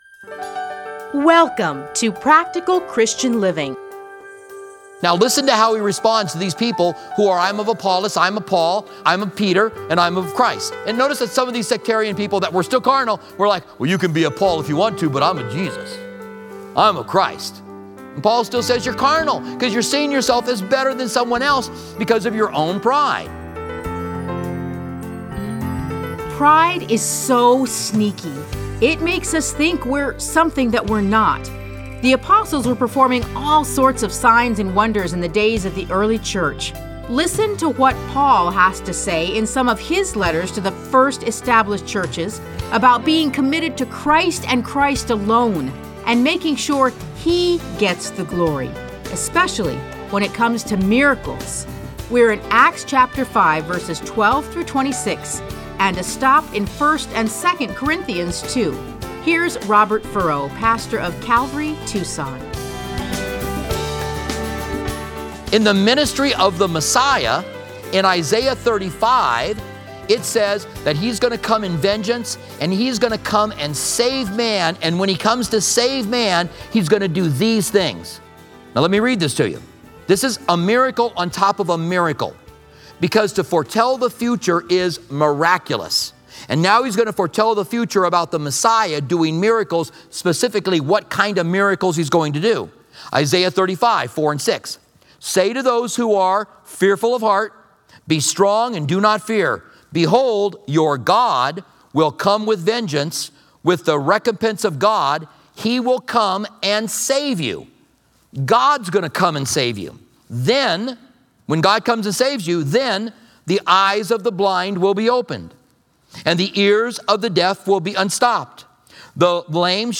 Listen to a teaching from Acts 5:12-22.